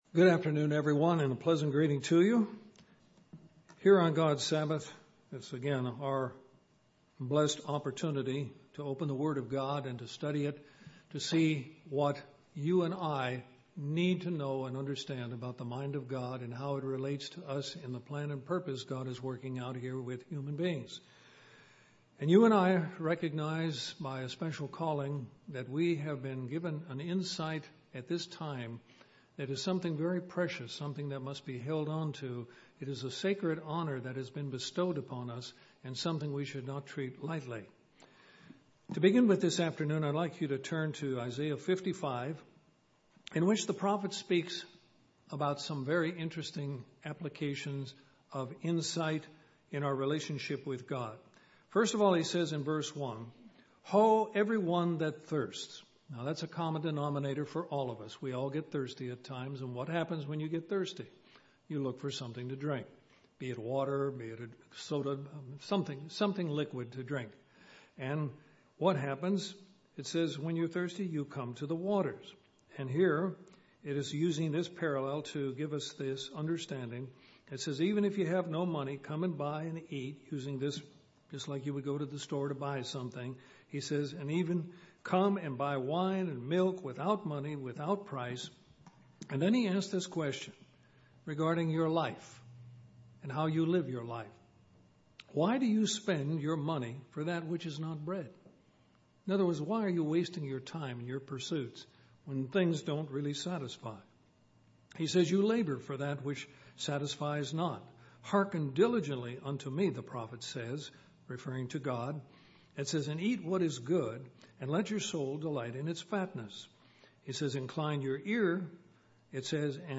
Armor Up! This sermon gives four valuable points to help us persevere in the right direction.